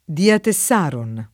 Diatessaron [ diat $SS aron ; alla greca diate SS# ron ]